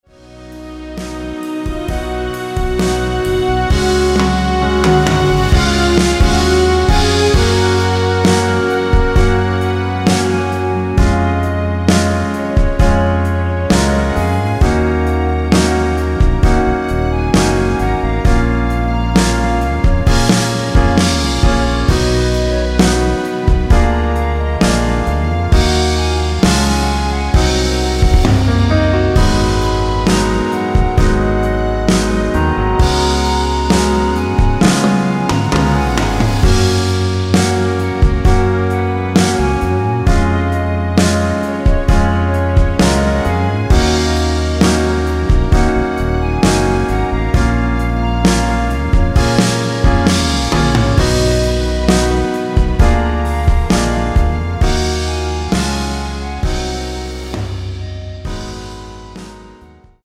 원키 멜로디 포함된 MR입니다.(미리듣기 확인)
F#
앞부분30초, 뒷부분30초씩 편집해서 올려 드리고 있습니다.
중간에 음이 끈어지고 다시 나오는 이유는